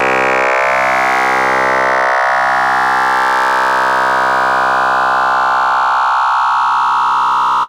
VCO ENV FM 1.wav